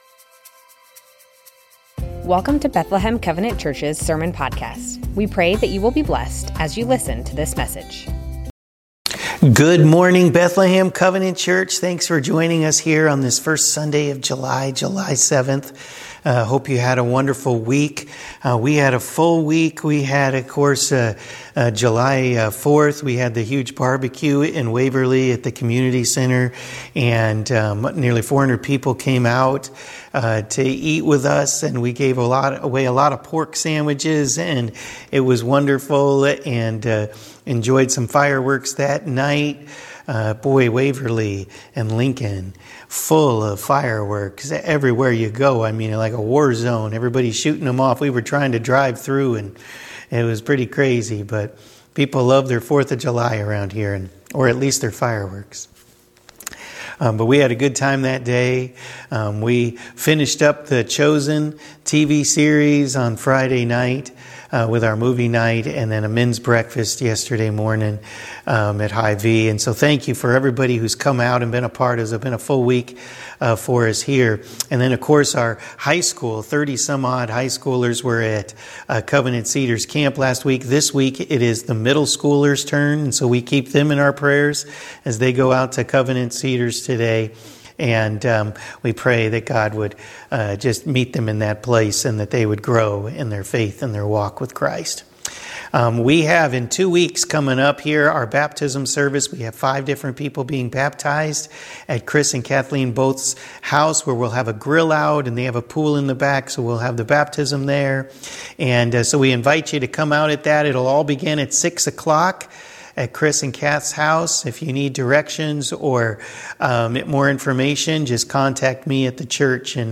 Bethlehem Covenant Church Sermons James - Patience Jul 07 2024 | 00:32:03 1x 00:00 / 00:32:03 Subscribe Share Spotify RSS Feed Share Link Embed